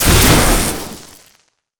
electric_lightning_blast_03.wav